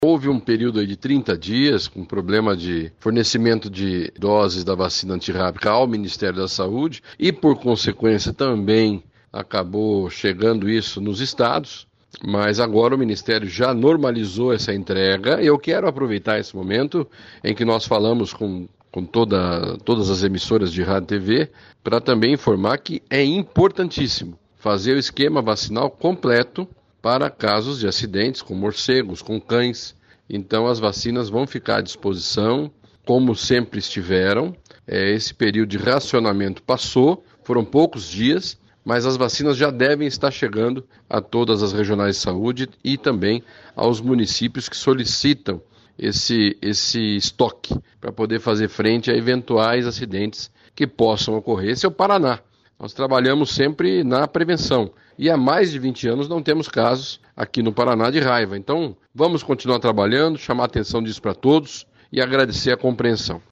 Sonora do secretário da Saúde, Beto Preto, sobre distribuição de vacina antirrábica nas Regionais de Saúde do Estado